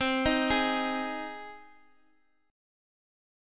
Cm#5 chord